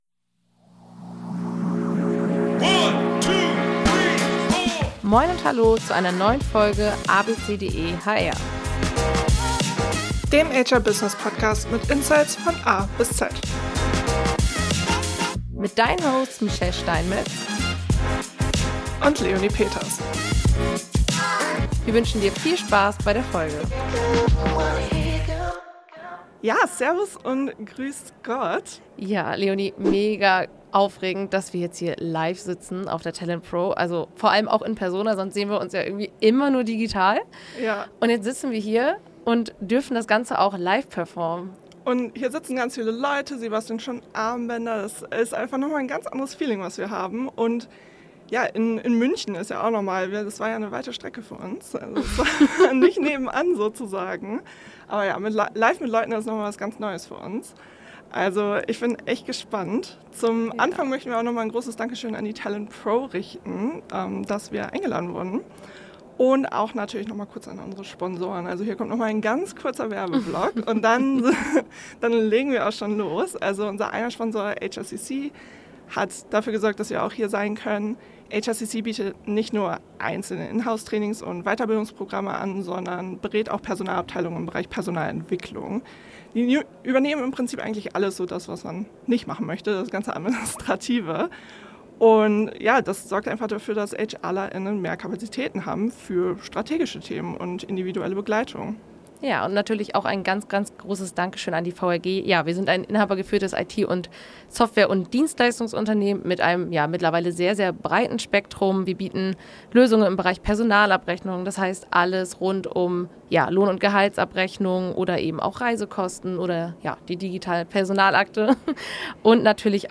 Wir waren in München auf der TALENTpro und durften unsere erste Live-Aufnahme machen.
Drei mutige Hörer:innen haben sich zu uns in den Pod gesetzt.